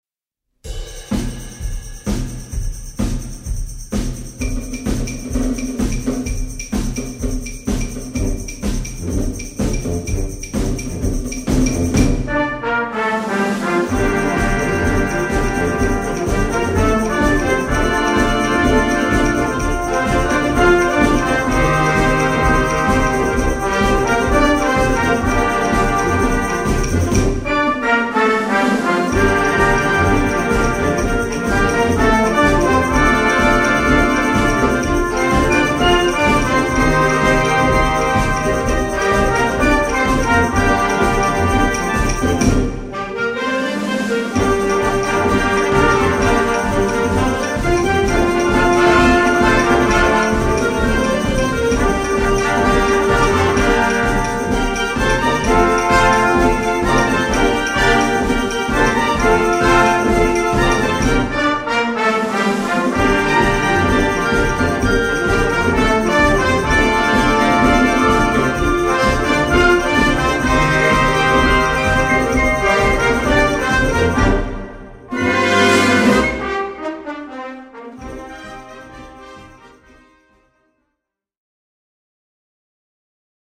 Recueil pour Harmonie/fanfare - Pop music